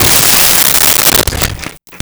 Window Shatter 02
Window Shatter 02.wav